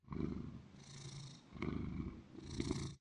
purr1.ogg